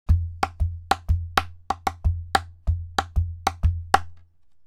Played by slapping side and to get a bass sound, hitting the floor or folded kapa (Hawaiian fabric) with the base.